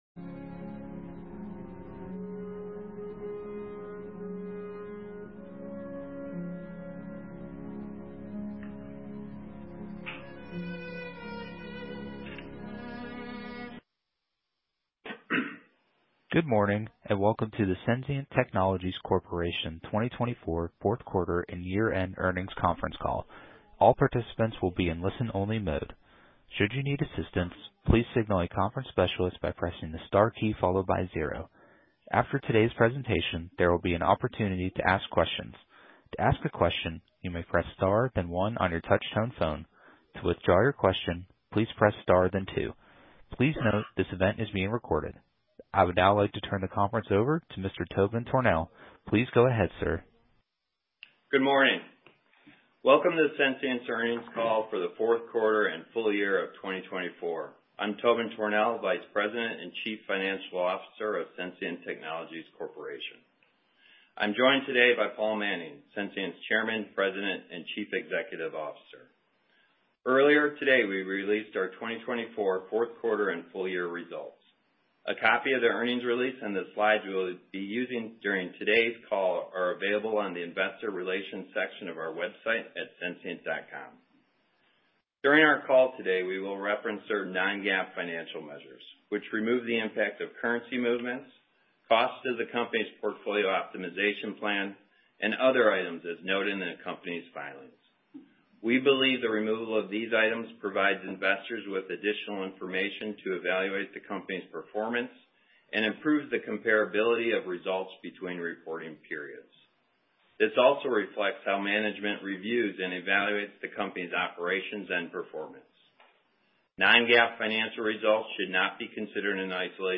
2024 Fourth Quarter and Year-End Earnings Release and Conference Call February 14, 2025 8:30 AM CT Webcast (opens in new window) Presentation (opens in new window) Transcript (opens in new window) Audio File (opens in new window)